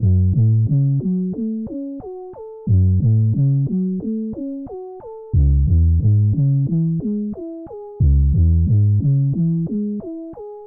bass1